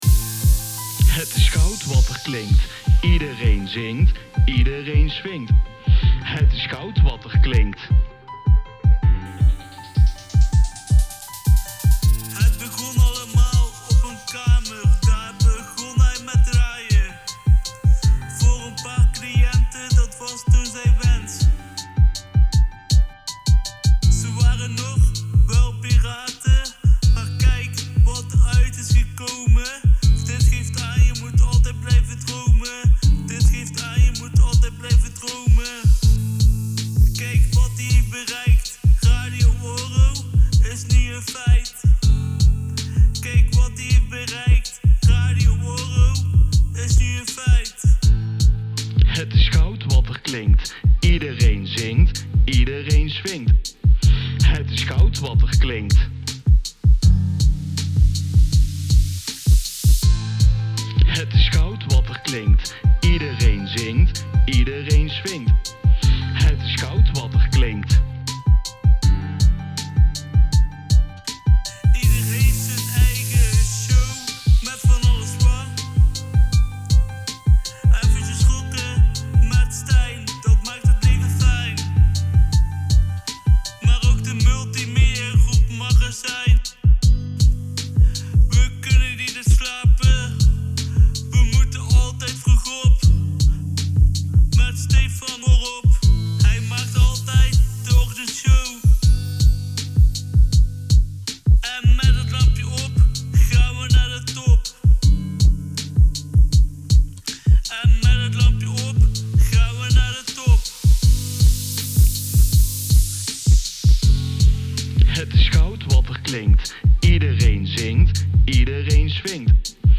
Vandaag (1 maart 2022) bestaat RadiORO namelijk 1 jaar en dat moet gevierd worden. Er is voor deze speciale gelegenheid een mooi lied gemaakt door de DJ’s met de naam ‘Chillie Willie’.